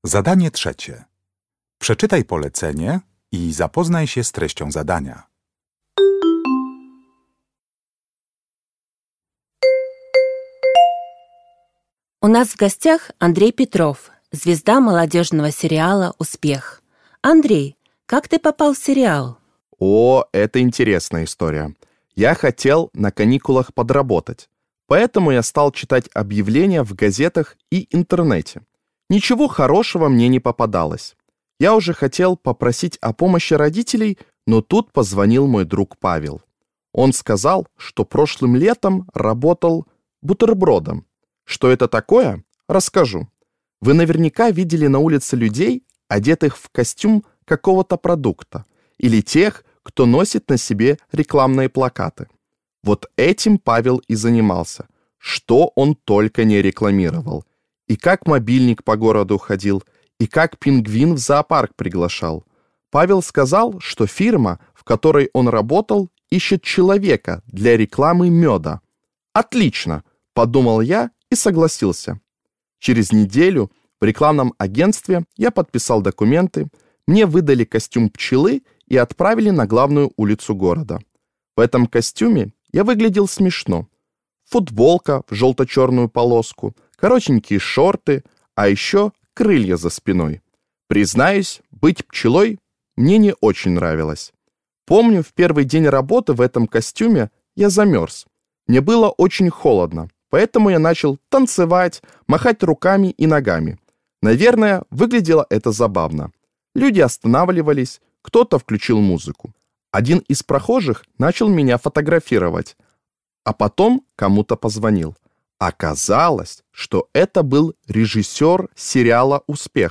Uruchamiając odtwarzacz z oryginalnym nagraniem CKE usłyszysz dwukrotnie wywiad z młodym aktorem.